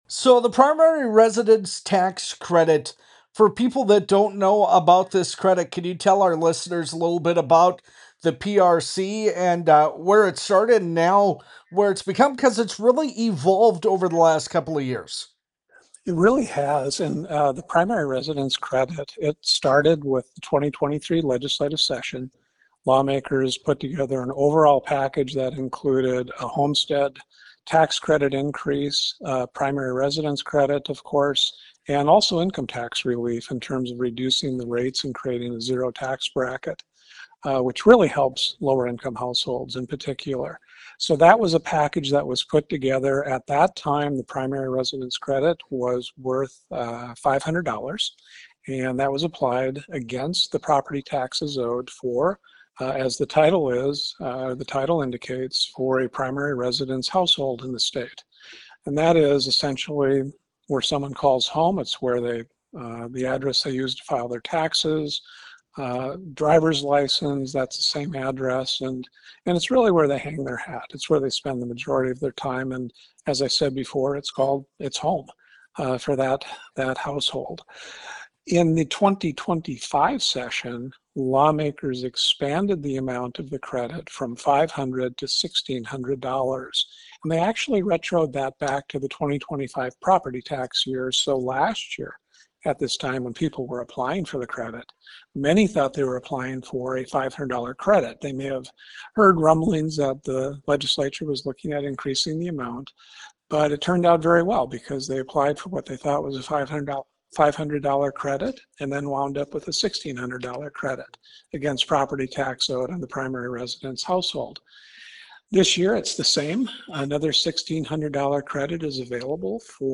interview with North Dakota Tax Commissioner Brian Kroshus